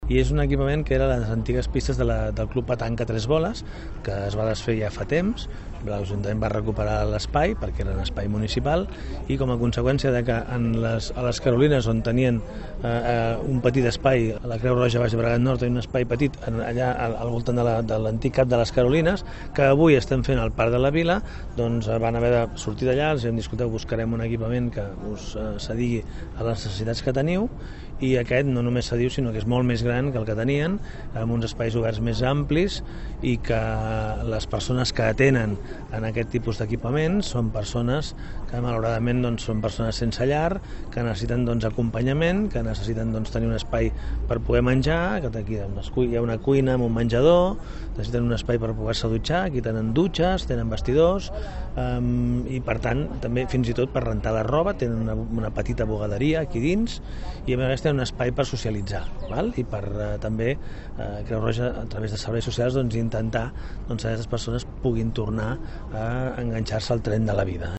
Visita institucional aquest matí a la nova ubicació de l’equipament de Creu Roja Baix Llobregat Nord, situat a la finca de la Torrassa.
Xavier Fonollosa, alcalde de Martorell